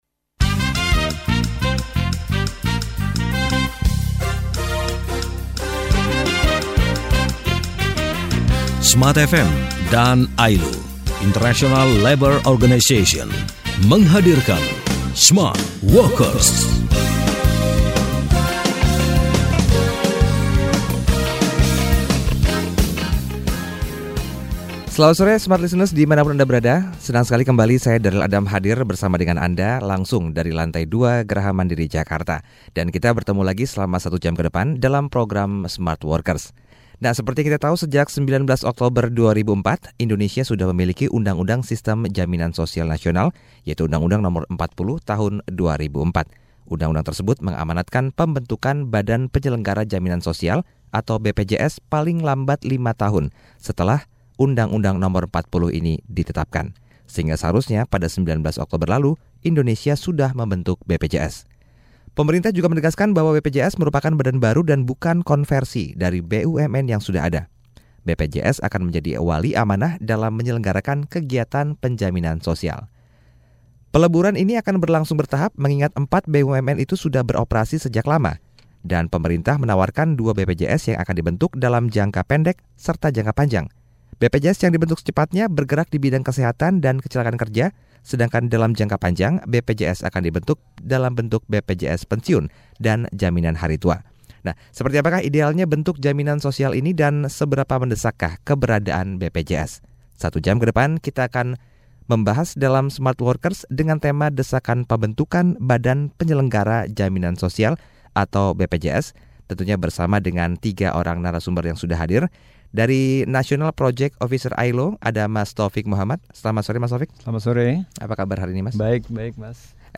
Interview Indonesia 30.05.2011